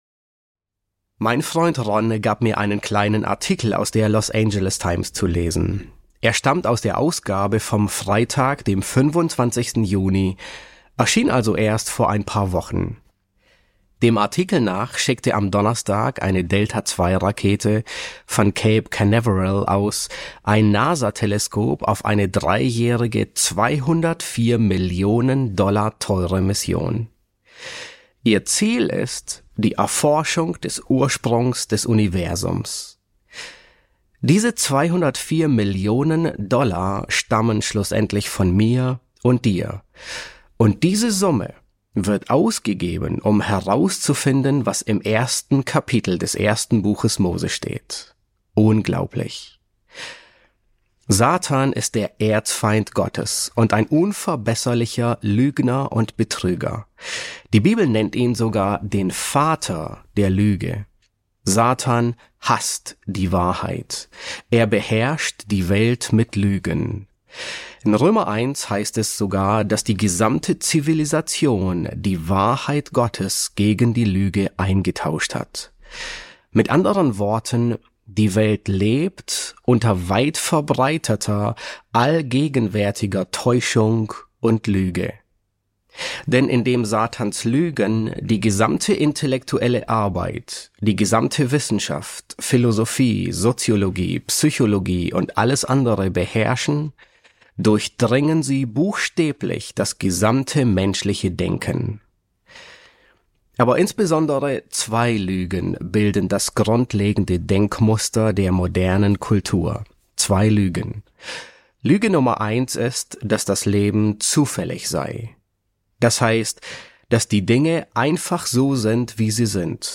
Predigten auf Deutsch